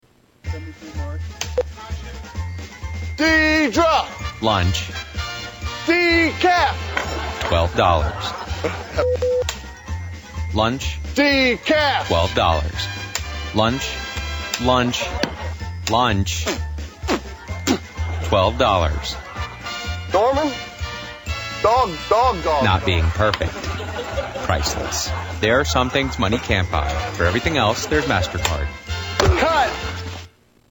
Lunch blooper commercial
Tags: Media MasterCard Advertisement Commercial MasterCard Clips